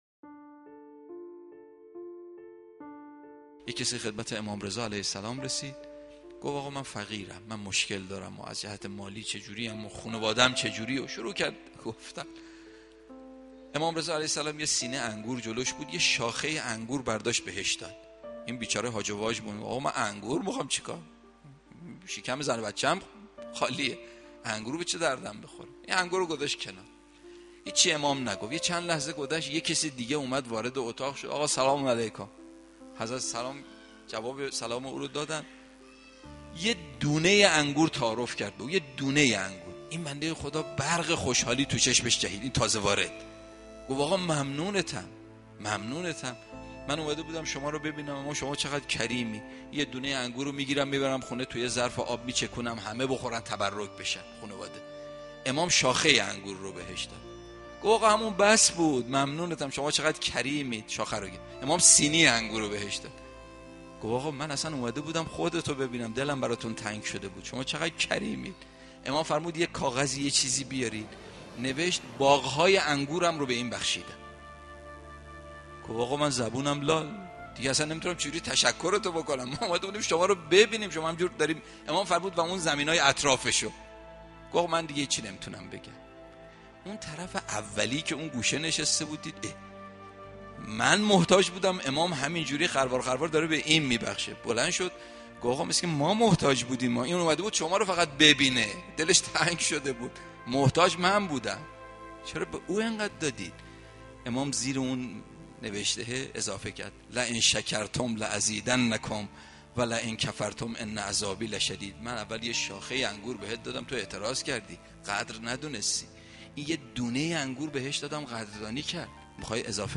سخنرانی مذهبی حکایت